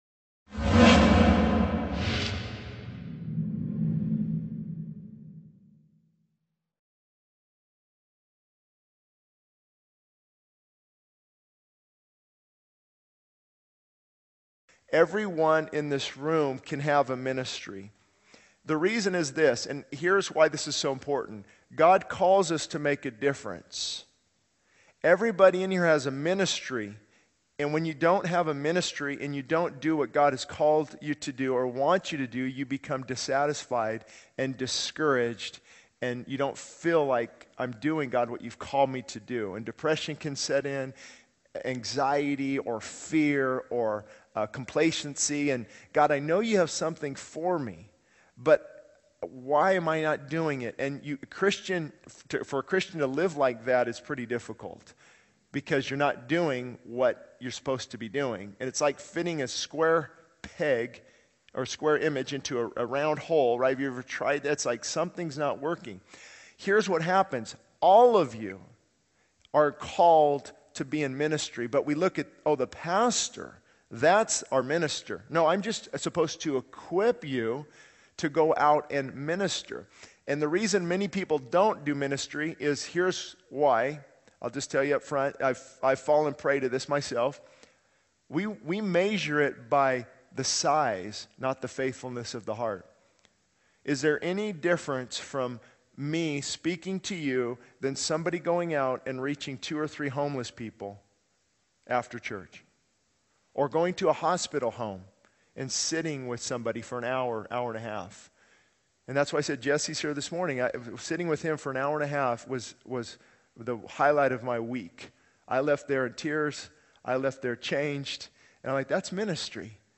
He encourages the congregation to recognize that ministry is not solely the pastor's responsibility but a calling for all Christians, regardless of the size or visibility of their efforts.